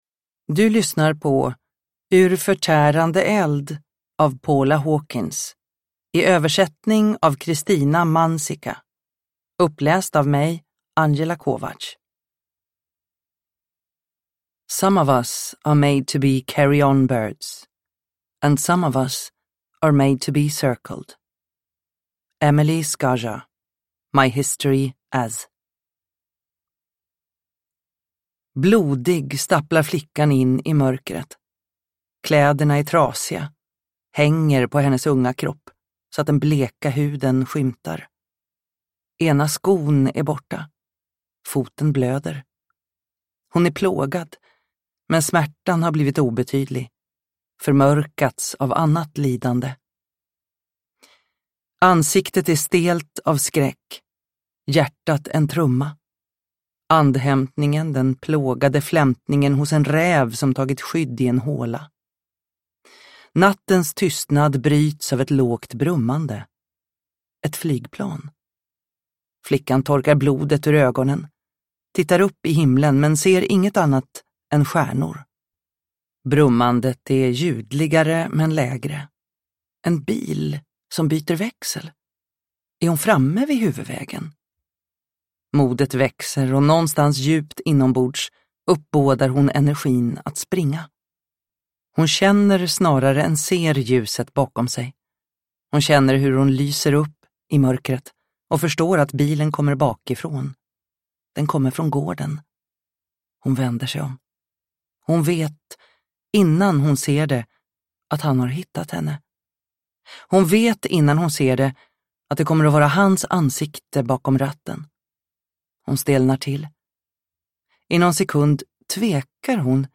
Ur förtärande eld – Ljudbok – Laddas ner